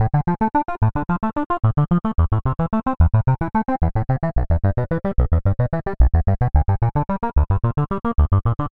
描述：低音琶音清唱
Tag: 110 bpm Electro Loops Bass Synth Loops 1.47 MB wav Key : Unknown